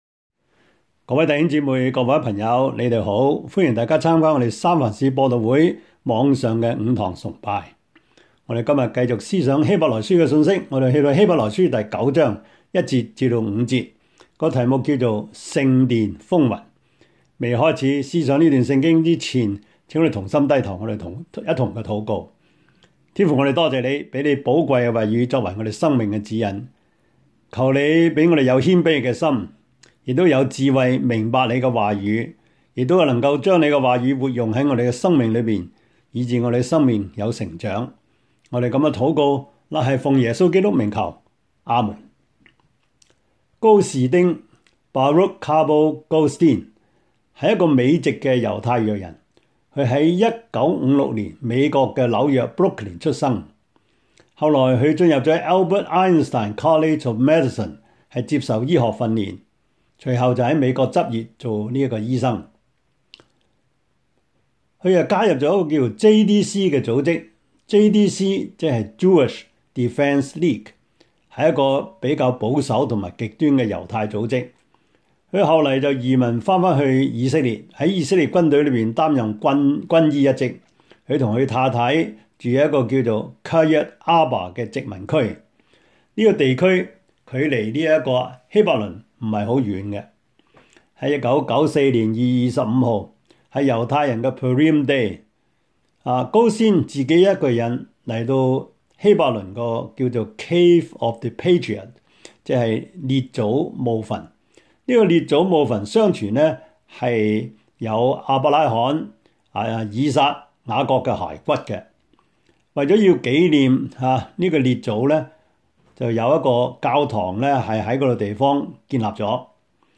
Service Type: 主日崇拜
Topics: 主日證道 « 盼望新天新地 如何讀聖經 – 第十課 »